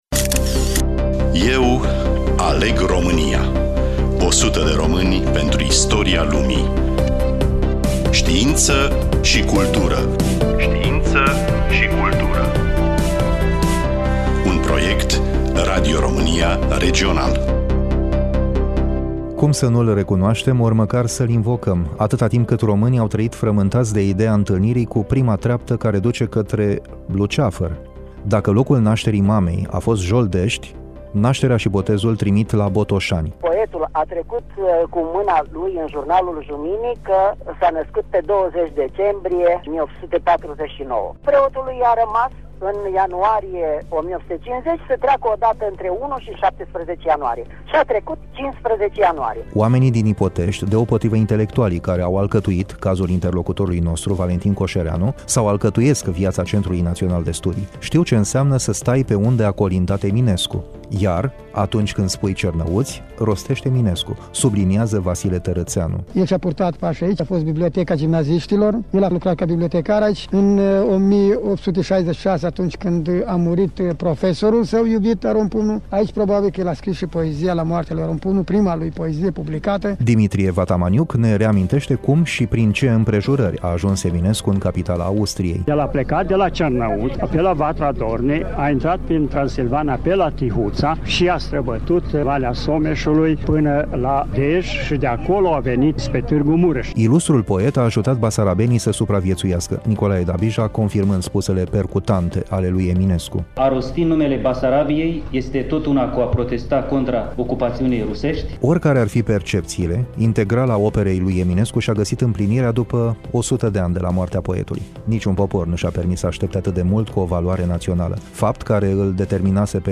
Studioul: RADIO ROMÂNIA IAȘI